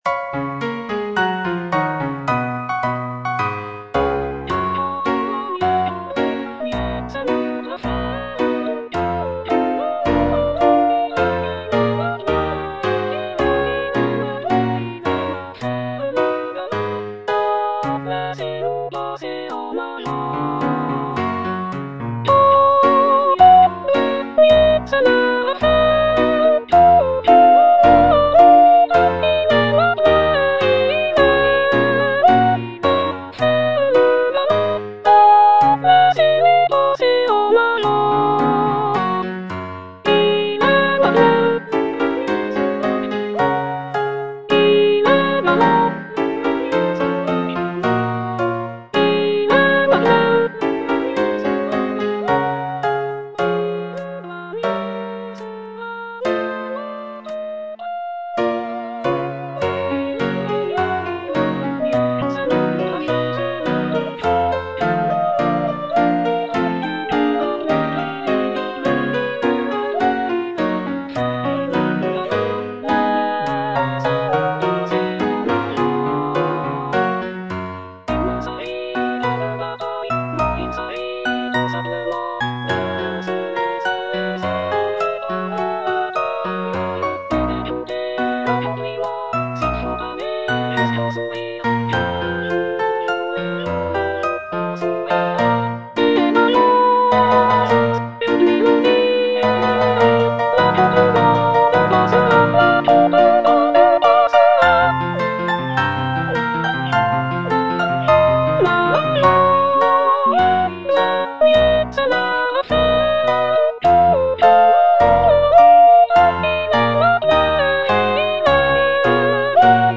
Choralplayer playing Choirs from
G. BIZET - CHOIRS FROM "CARMEN" Quant au douanier (soprano I) (Voice with metronome) Ads stop: auto-stop